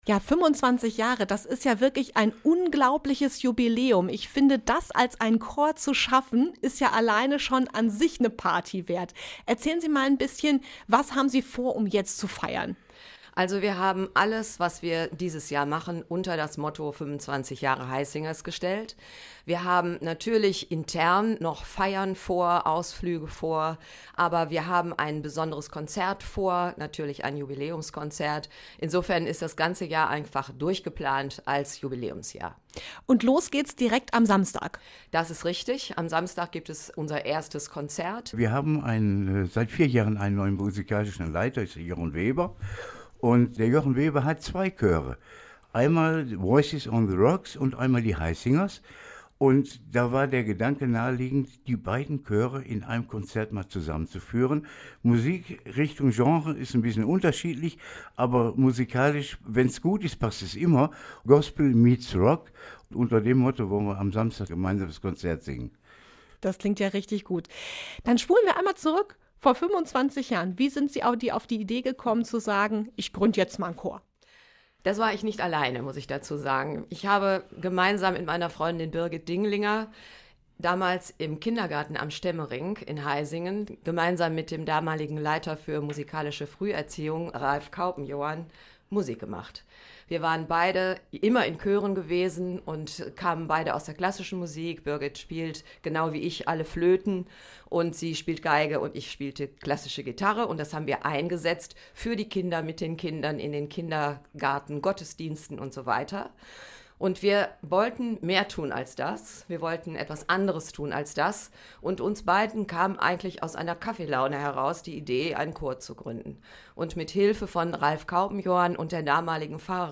Der Essener Gospelchor The Heisingers wird 25 Jahre alt. Im Interview mit der Himmel und Essen-Redaktion haben zwei Mitglieder erzählt, wie der Chor entstanden ist, wie sich die Mitglieder über die Corona-Zeit motiviert haben und an welchen ungewöhnlichen Orten die Sängerinnen und Sänger schon aufgetreten sind.